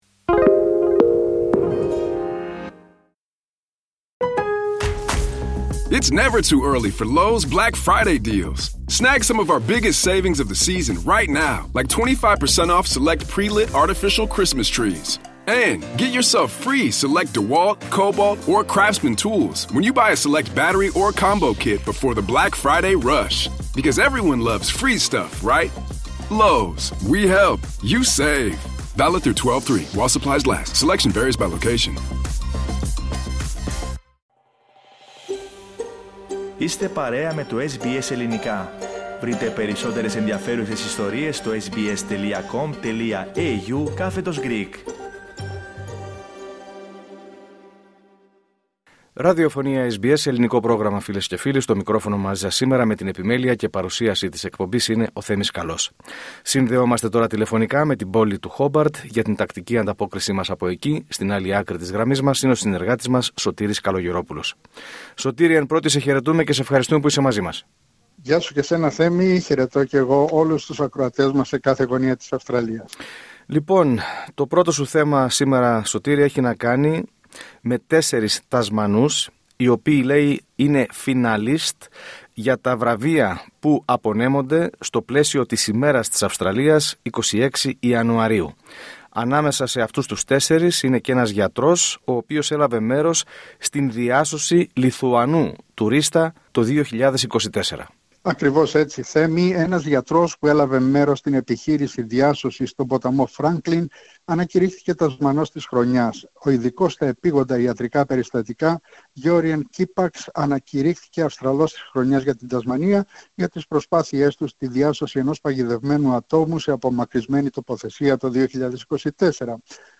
Ανταπόκριση-Τασμανία: Σπάνιο ψάρι που προμηνύει καταστροφές ξεβράστηκε στην Τασμανία